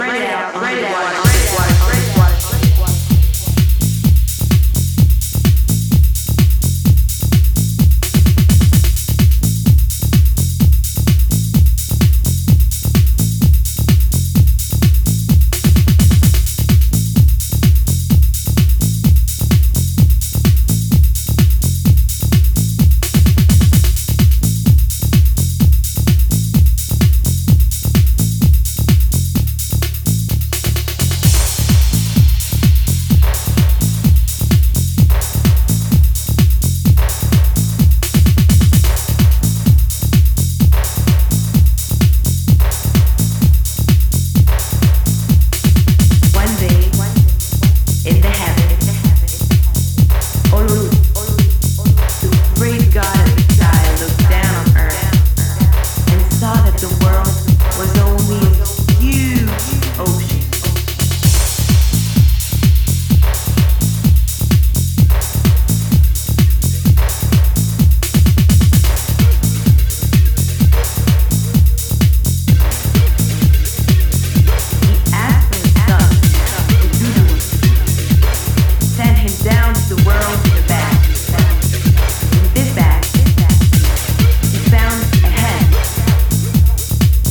blending deep rhythm, swing, and hypnotic warmth.